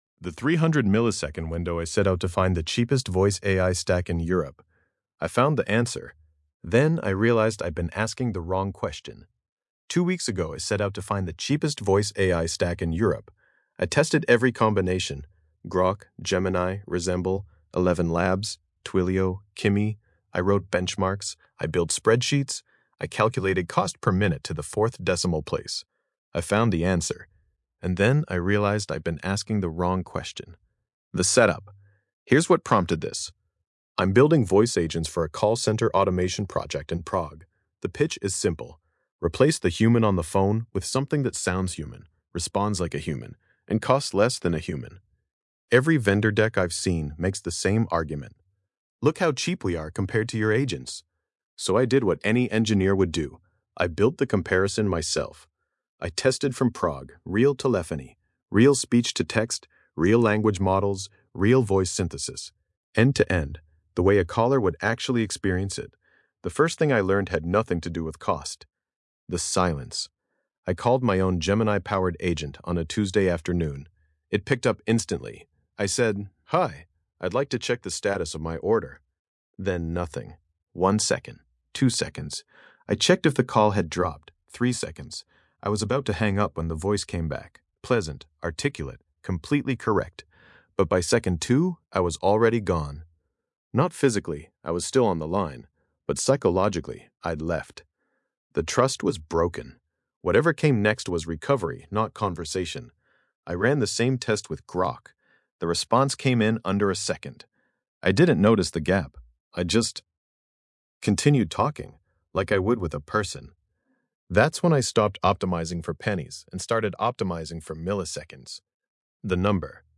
Podcast-style audio version of this essay, generated with the Grok Voice API.